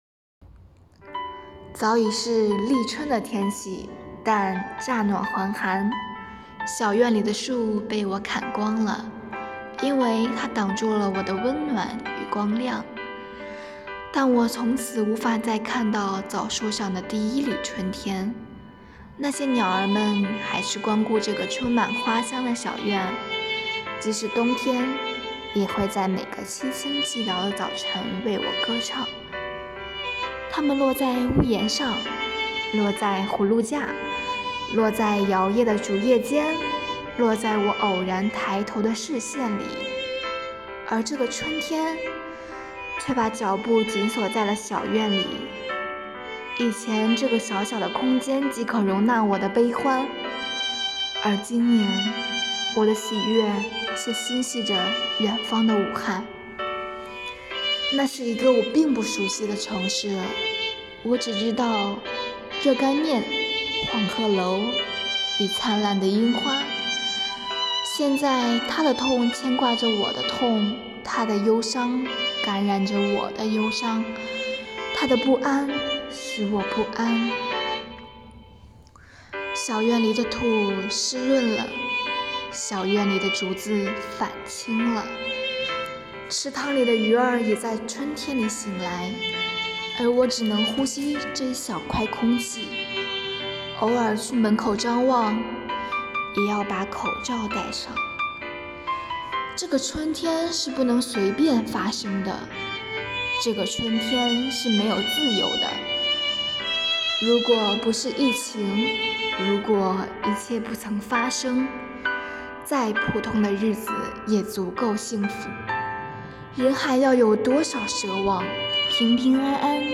“悦读·新知·致敬”主题朗读比赛|优秀奖